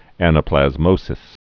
(ănə-plăz-mōsĭs)